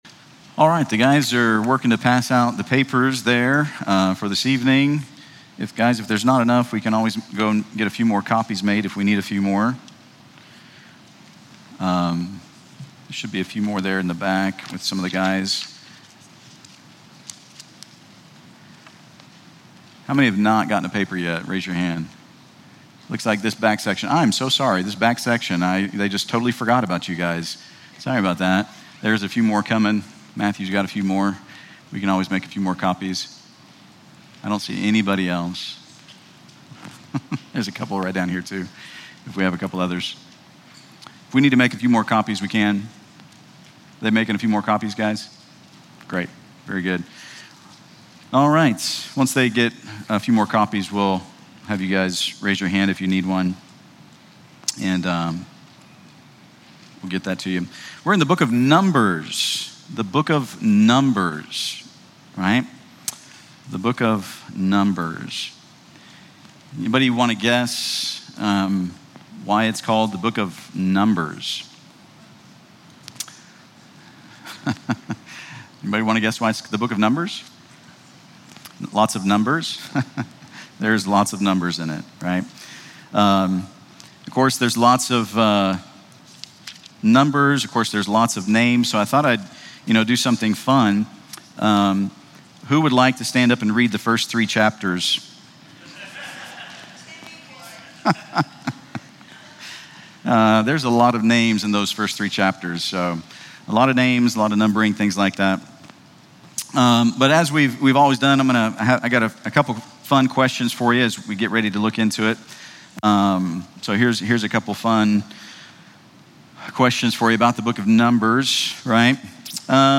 Enjoy covering Israel’s 40-year wilderness journey, the sermon reveals Jesus as the pillar of cloud and fire, manna, water from the rock, and the brazen serpent as we take a Snapshot look at the book of Numbers. It emphasizes trusting God over rebellion, which leads to aimless wandering. Learn how to follow Christ, our divine Joshua, to fulfill God’s purpose through faithful service.